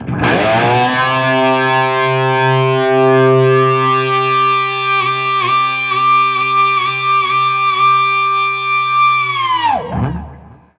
DIVEBOMB 3-R.wav